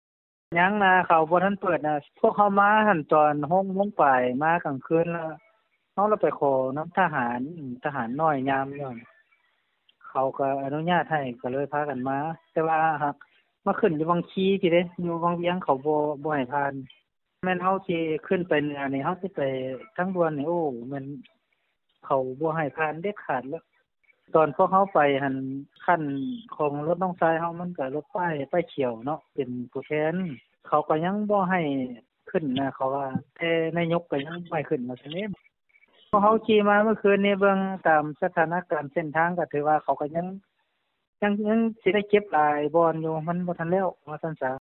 ສຽງຂອງນັກທ່ອງທ່ຽວທີ່ເດີນທາງມາຈາກວັງວຽງ